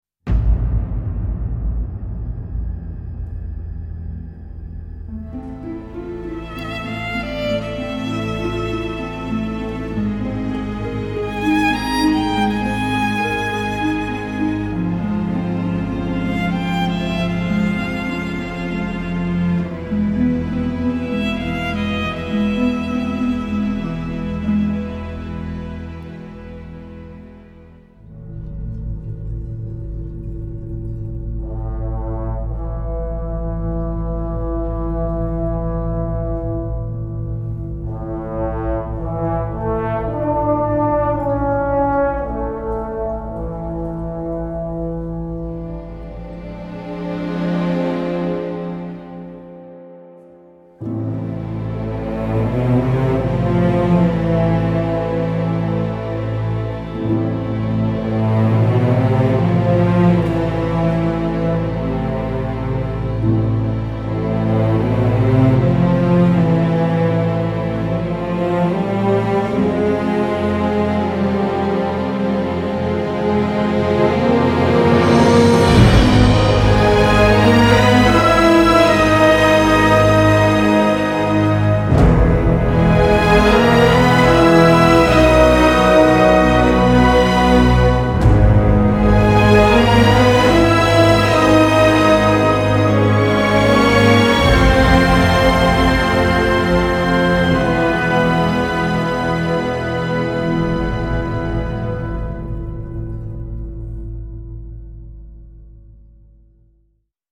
Artist: Instrumental